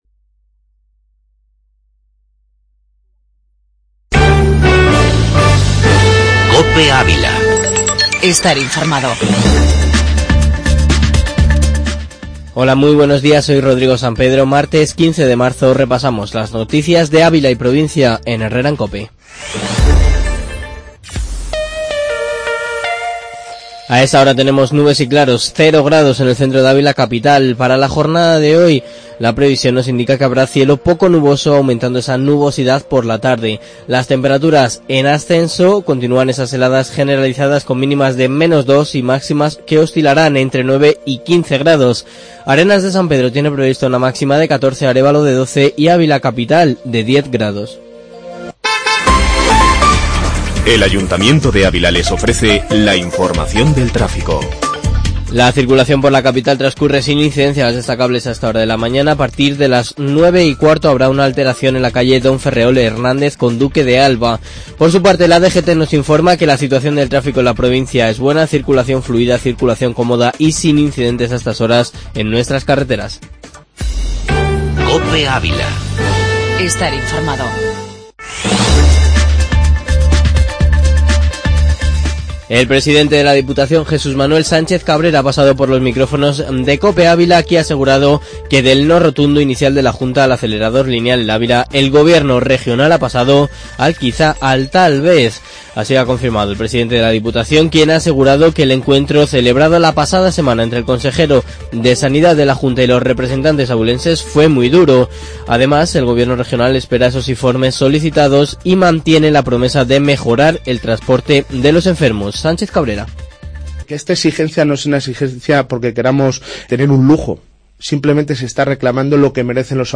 Informativo matinal en 'Herrera en Cope'.